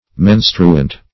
Meaning of menstruant. menstruant synonyms, pronunciation, spelling and more from Free Dictionary.
Search Result for " menstruant" : The Collaborative International Dictionary of English v.0.48: Menstruant \Men"stru*ant\, a. [L. menstruans, p. pr. of menstruare to have a monthly term, fr. menstruus.